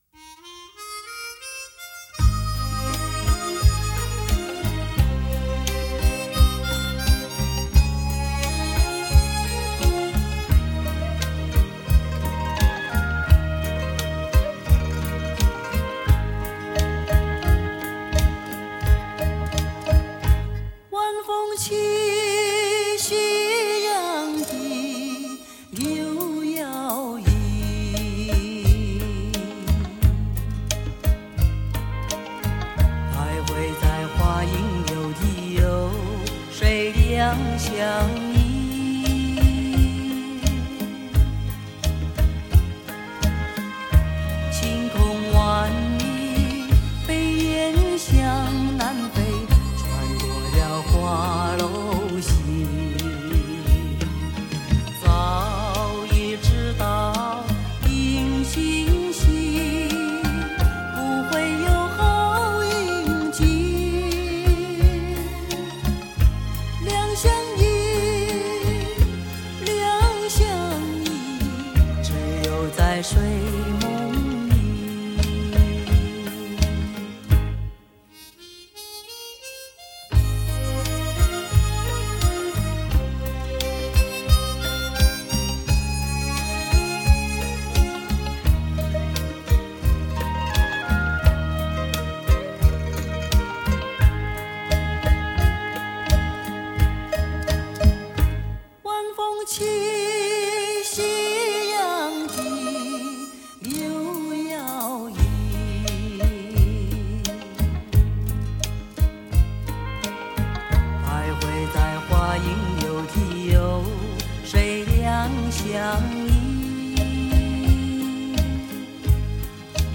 低沉嗓音 重新编曲 极品国语"老歌"专辑